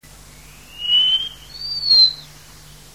Pomurnik - Tichodroma muraria